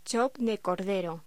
Locución: Chop de cordero